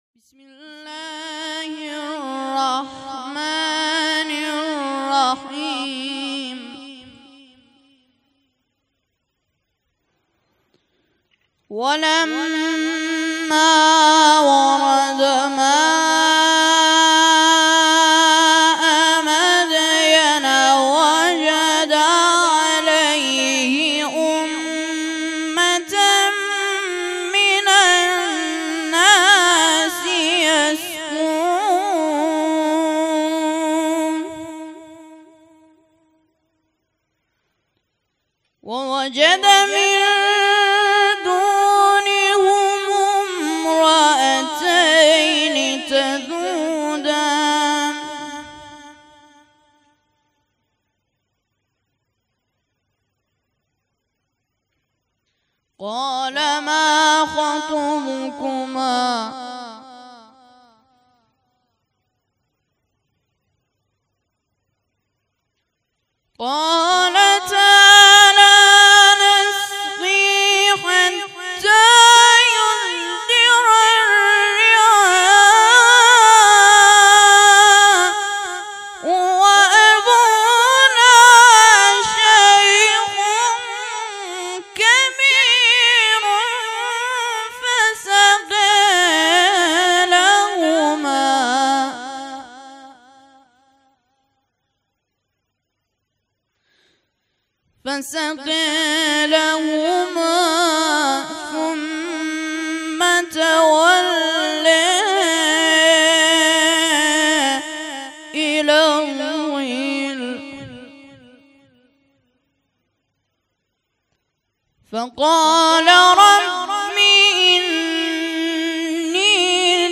مراسم عزاداری محرم ۱۴۰۴